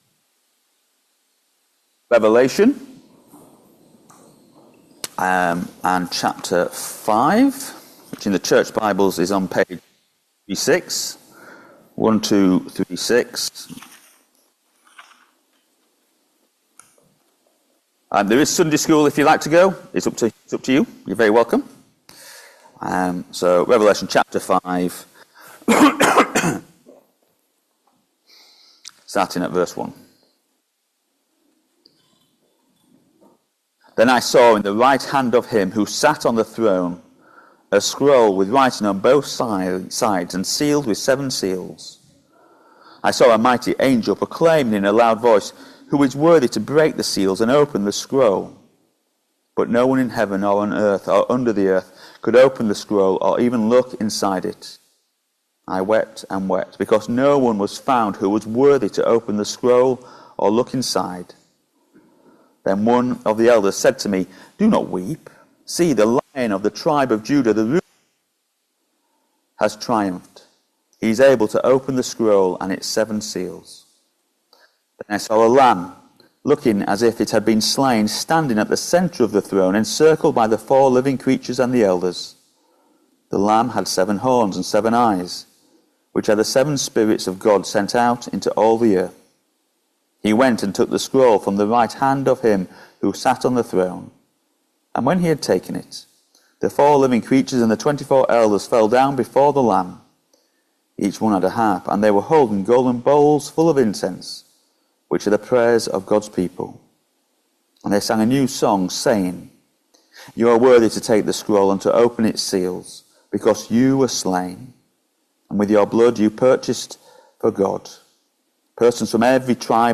Revelation 5vv1-14 Service Type: Sunday Morning Service Topics